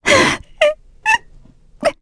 Valance-Vox_Sad_kr_b.wav